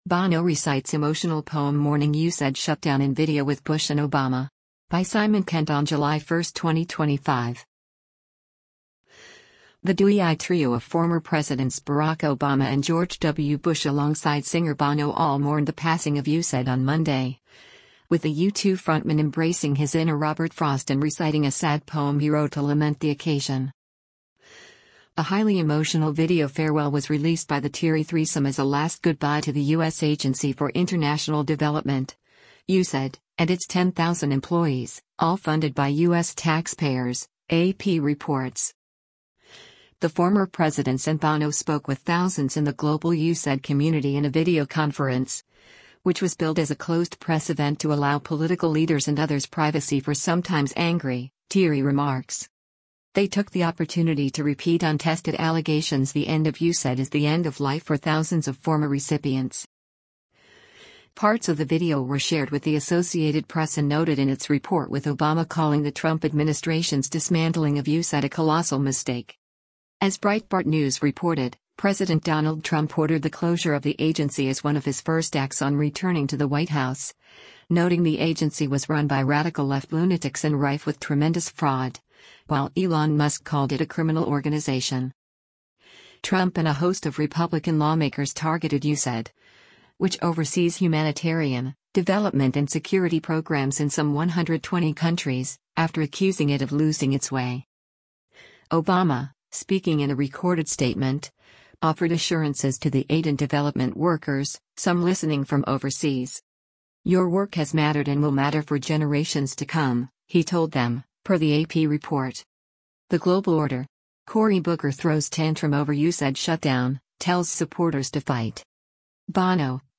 The former presidents and Bono spoke with thousands in the global USAID community in a videoconference, which was billed as a closed-press event to allow political leaders and others privacy for sometimes angry, teary remarks.
Obama, speaking in a recorded statement, offered assurances to the aid and development workers, some listening from overseas.
Bono spoke passionately as he recited a poem he had written to the agency and its gutting. He spoke of child deaths due to malnutrition, in a reference to people — millions, according to USAID supporters — who will allegedly suffer because of the U.S. cuts to funding for health and other programs abroad.